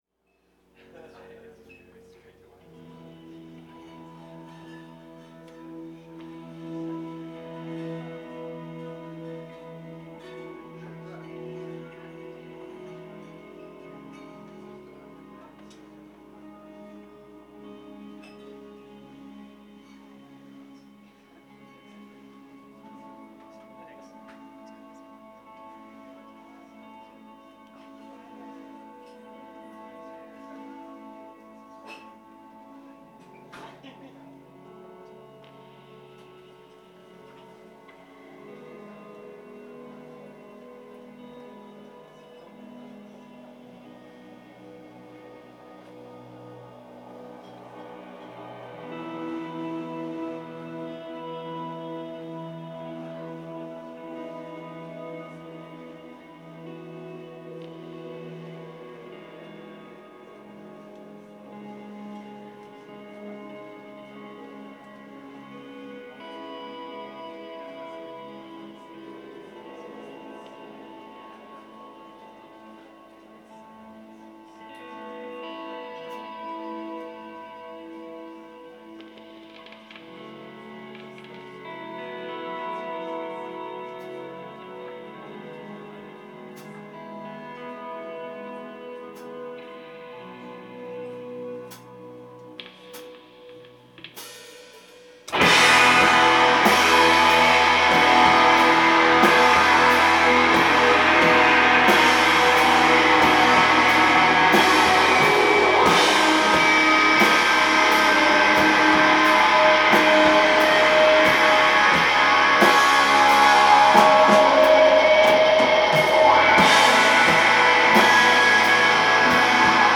live at the Lizard Lounge
Cambridge, MA